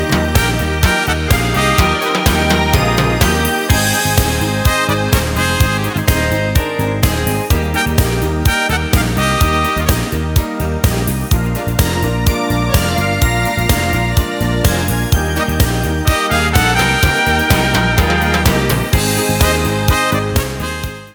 Wollen Sie auch die Karaoke-Version dazu?